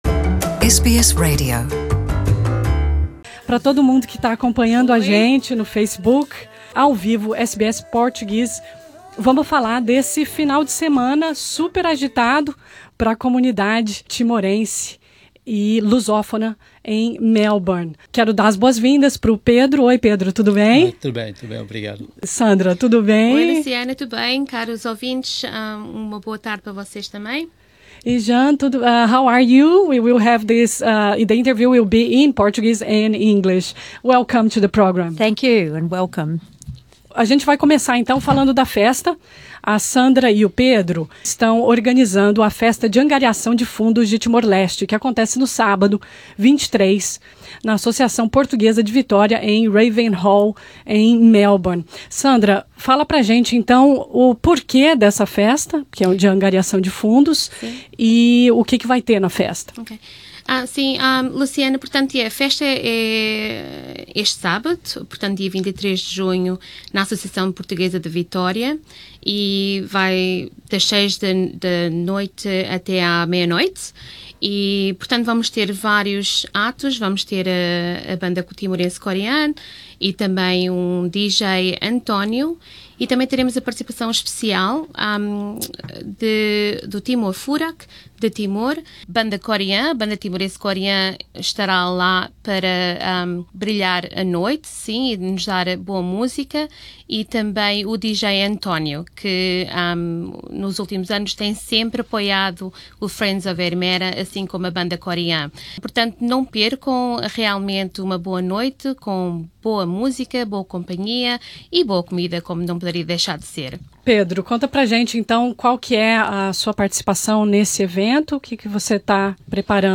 Em junho o grupo timorense Amigos de Ermera (Friends of Ermera) organizou a Festa de Angariação de Fundos e o lançamento do livro From Timor Leste to Australia. Ouça entrevista